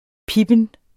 Udtale [ ˈpibən ]